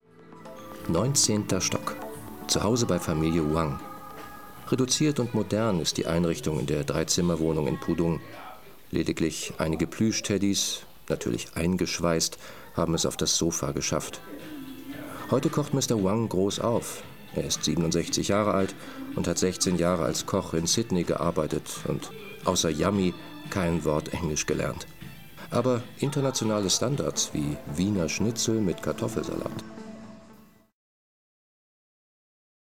Sprecher deutsch. Doku-Kommentare, voice-over, Imagefilme, Werbung, Synchron, Öffentliche Lesungen, Hörspiele, Hörbücher u.v.m.
norddeutsch
Sprechprobe: Werbung (Muttersprache):
german voice over talent